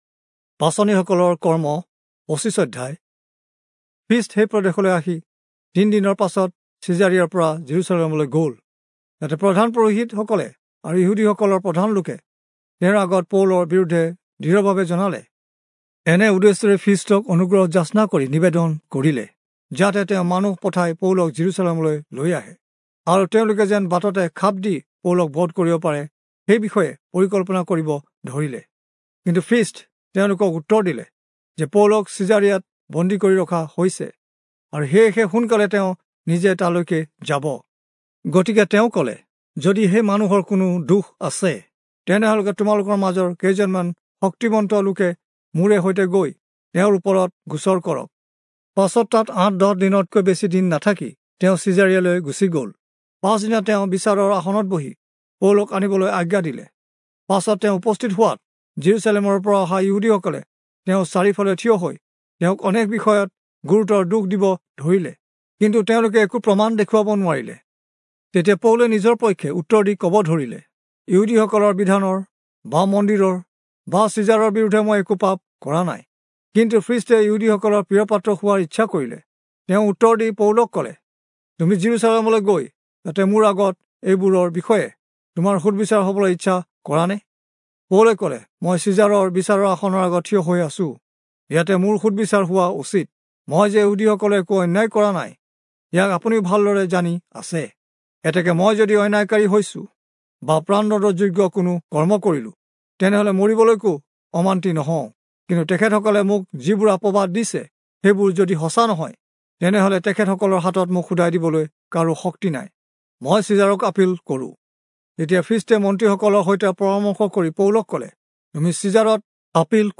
Assamese Audio Bible - Acts 12 in Gntbrp bible version